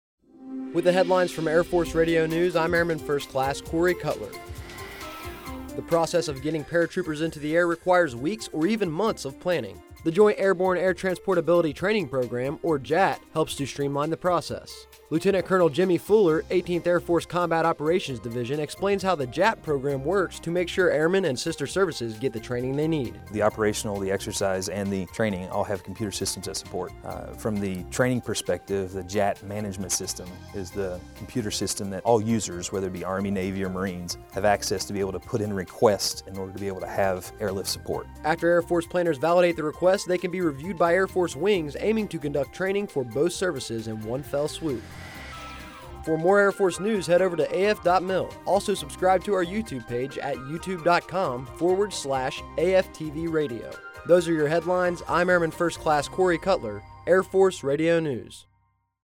Air Force Radio News A 26 August 2016